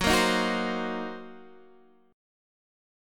Gb6b5 chord